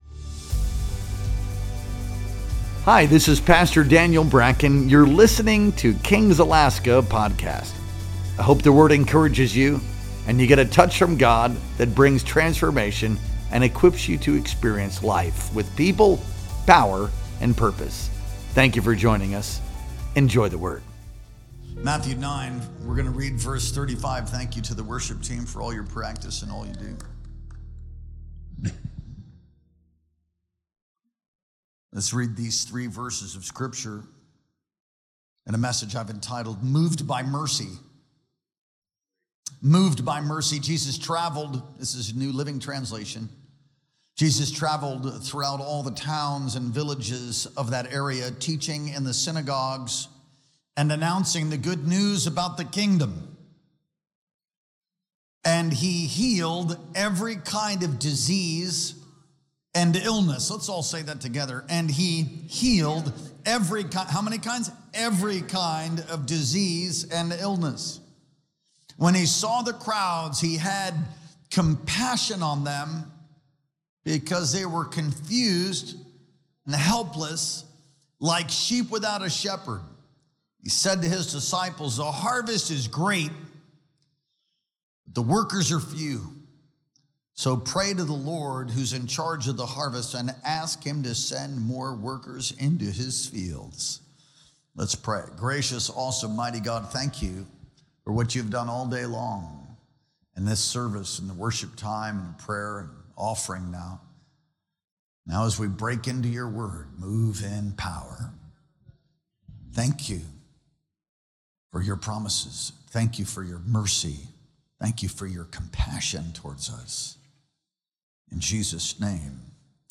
Our Sunday Night Worship Experience streamed live on July 27th, 2025.